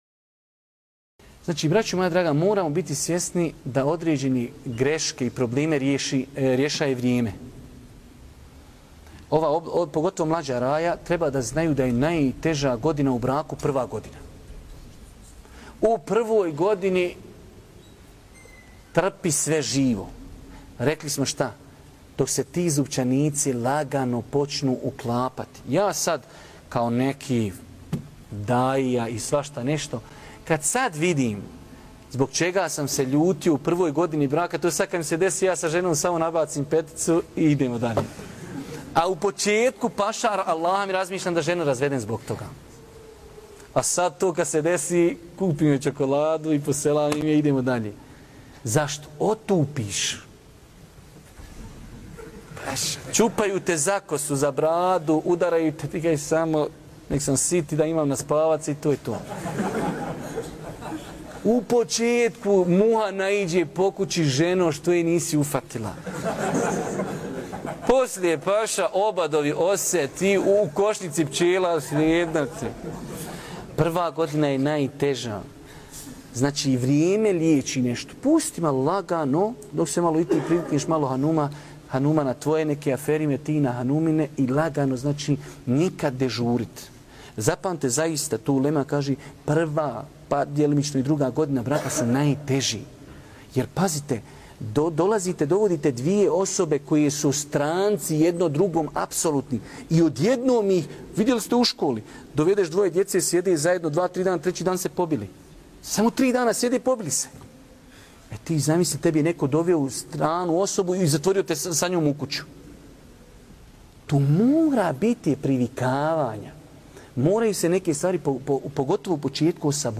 u kratkom predavanju